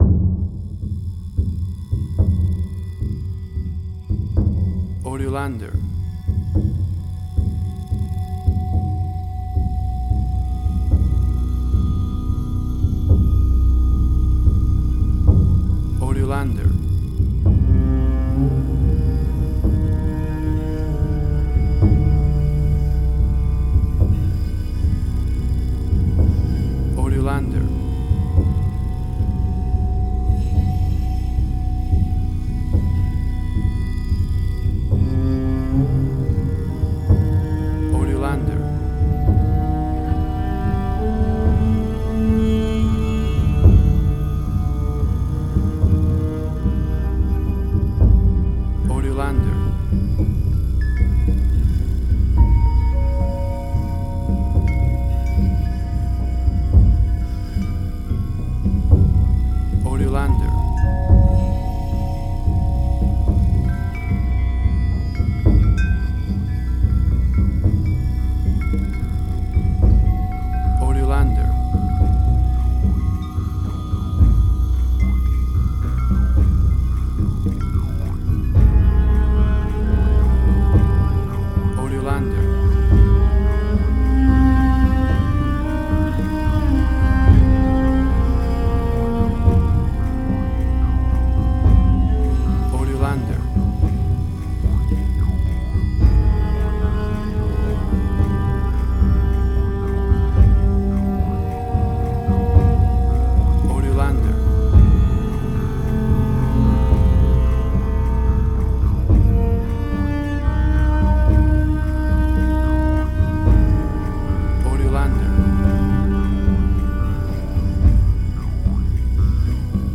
Modern spaghetti Western
Tempo (BPM): 110